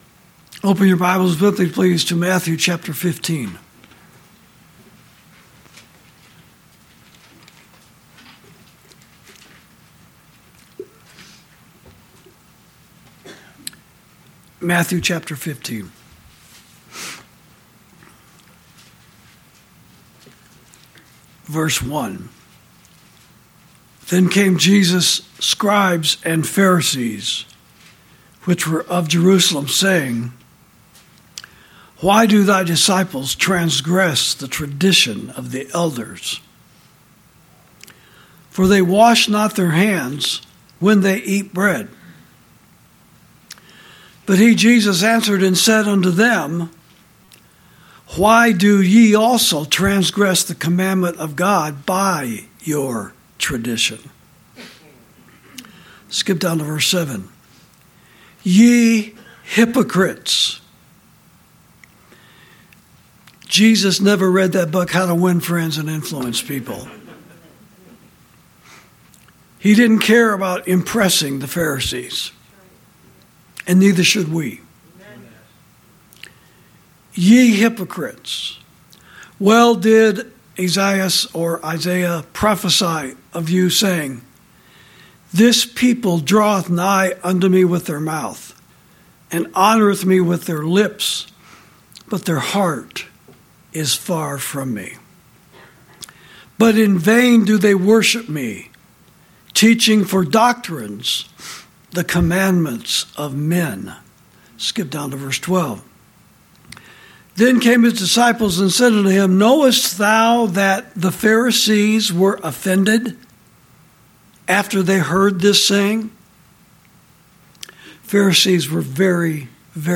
Sermons > Blind Leaders Of The Blind